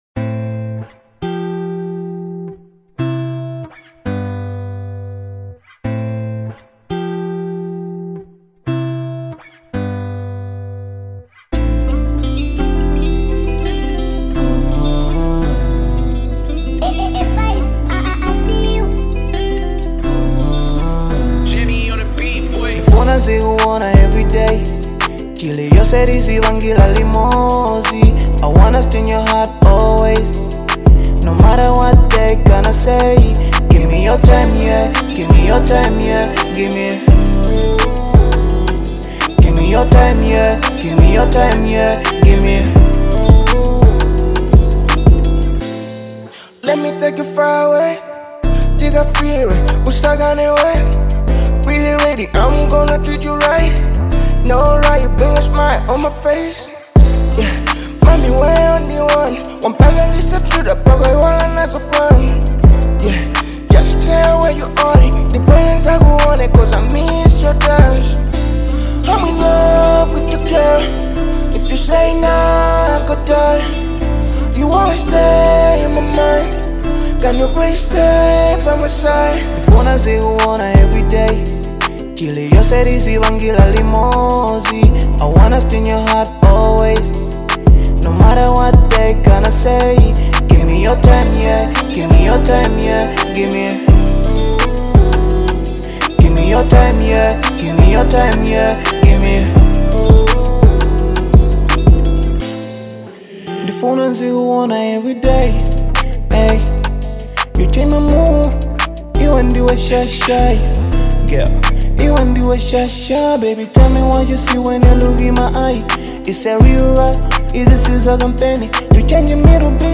Genre : Hiphop/Rap